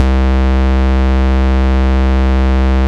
Index of /90_sSampleCDs/Roland - Rhythm Section/BS _Synth Bass 1/BS _Wave Bass